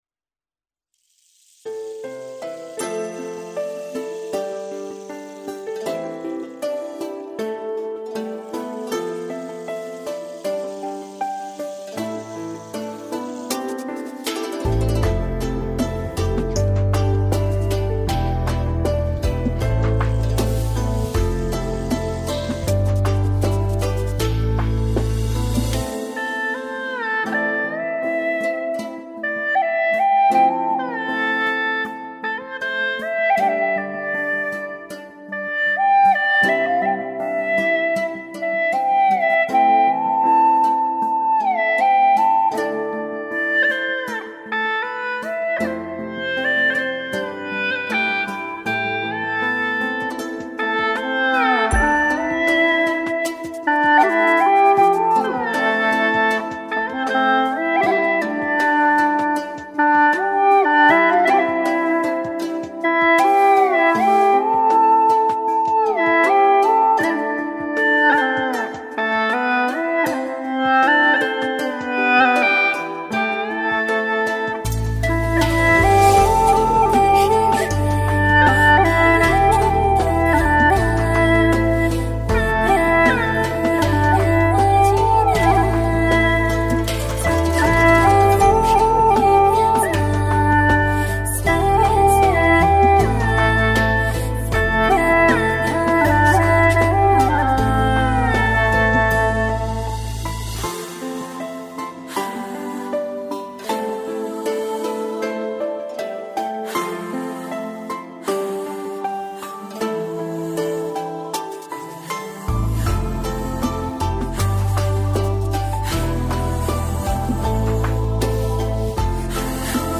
调式 : C 曲类 : 古风